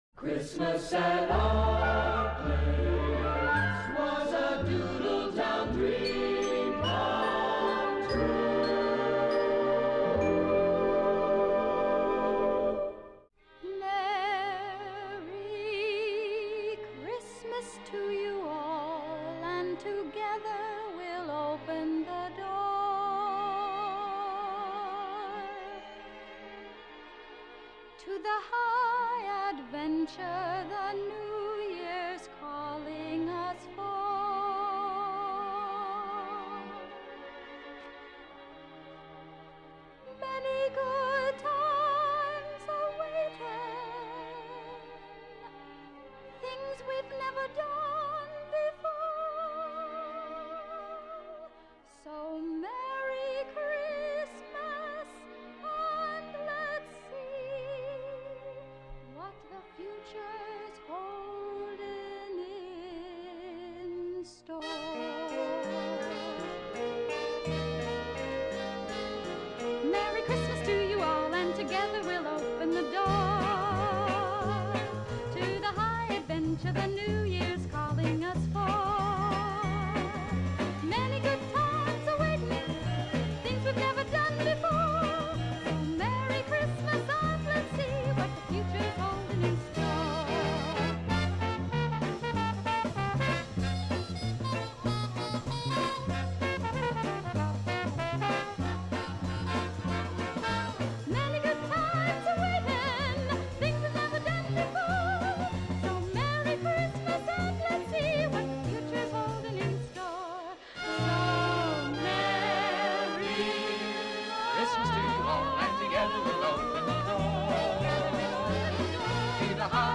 Featuring 26 jangling ditties to bring you back to do.